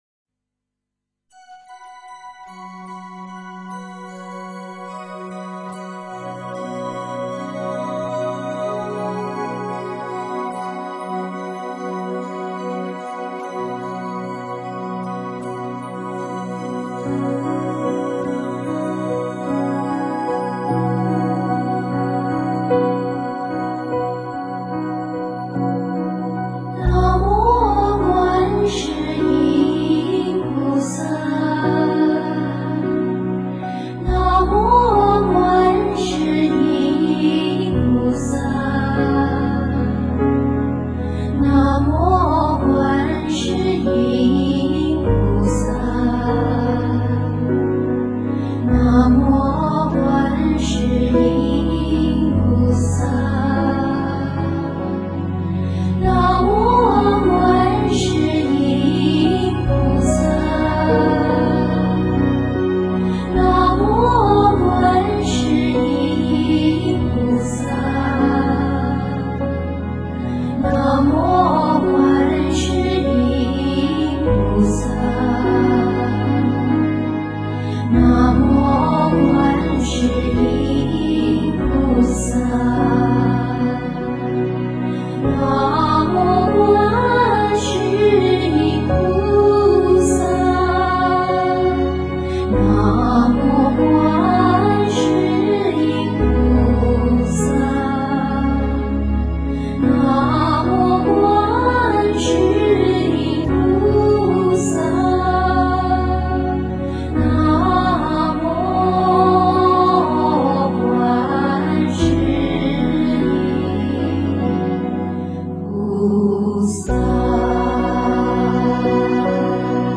優美清新的旋律與柔和親切的唱誦